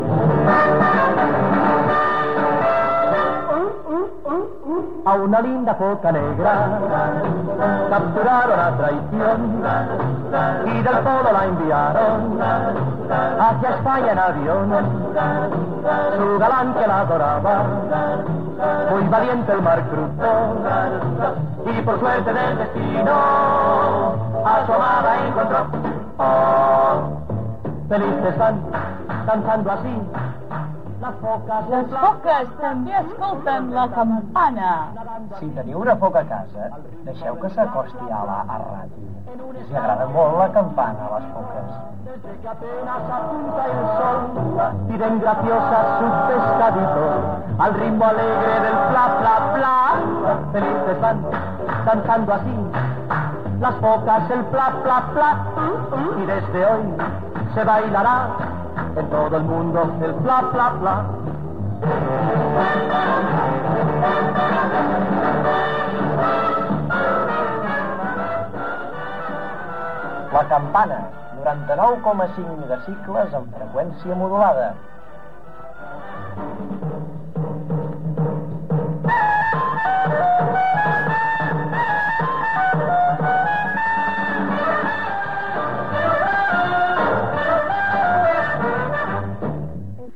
Indicatiu de l'emissora.
FM